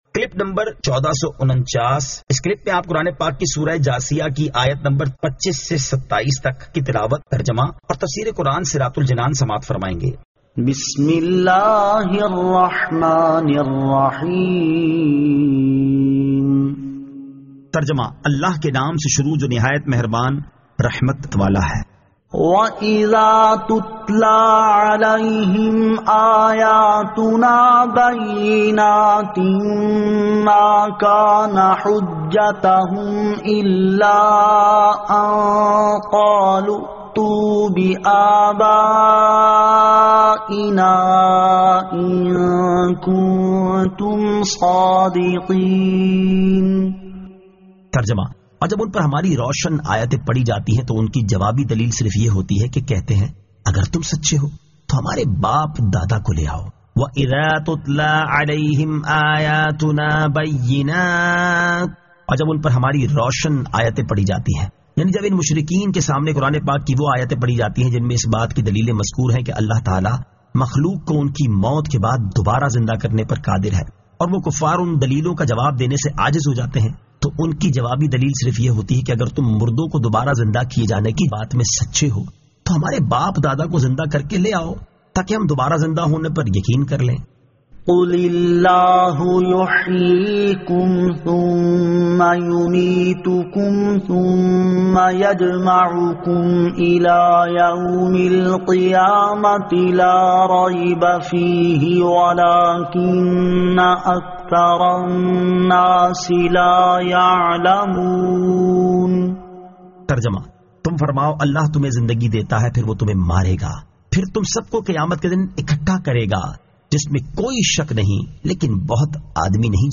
Surah Al-Jathiyah 25 To 27 Tilawat , Tarjama , Tafseer